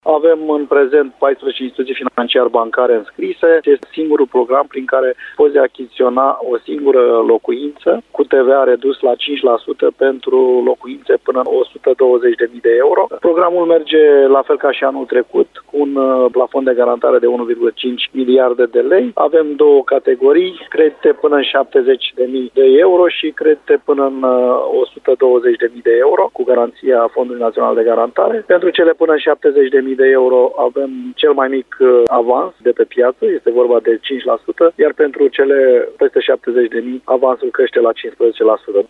Dumitru Nancu, președintele Fondului Național de Garantare a Creditelor.